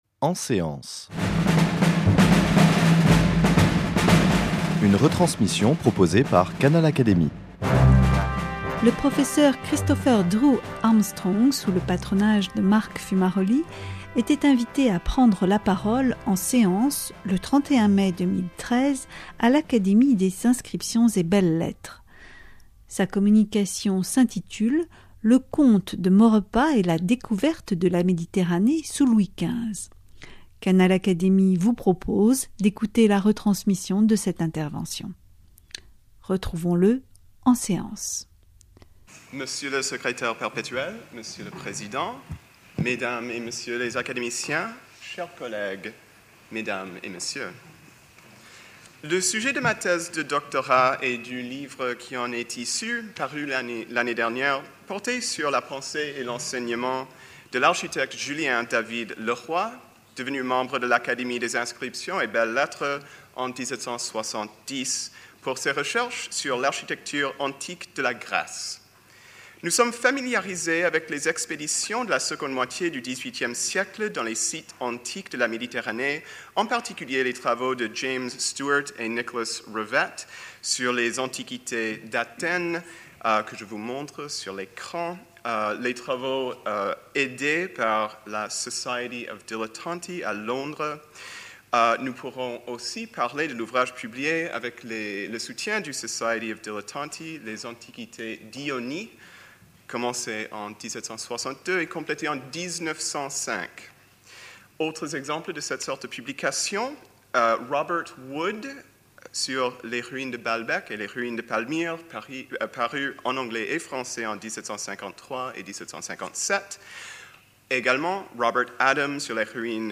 Canal Académie vous propose d’écouter la retransmission de cette intervention.